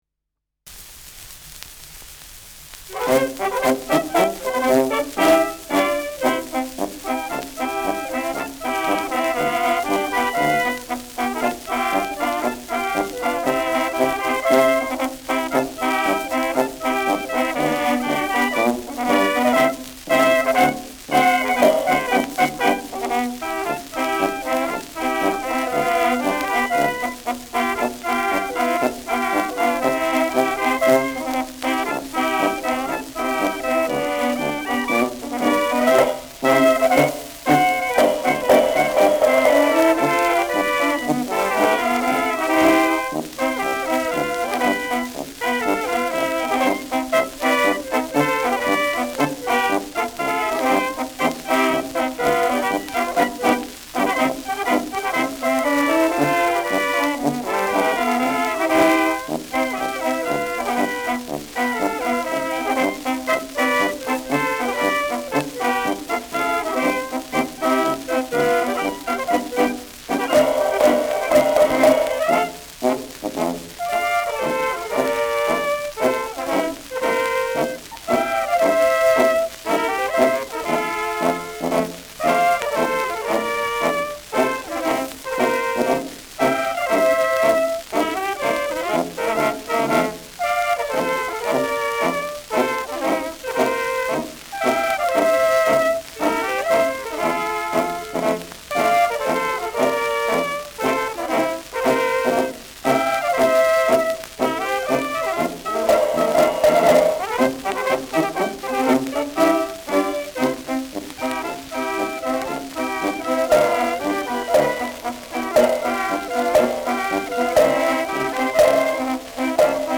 Schellackplatte
leichtes Rauschen
Bauernkapelle Salzburger Alpinia (Interpretation)